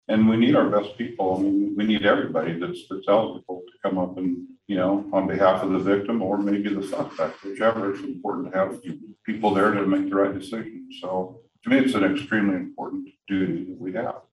Commission Chair Greg Riat, a former Sheriff agreed with McKee, pointing to the importance of jurors to the justice system.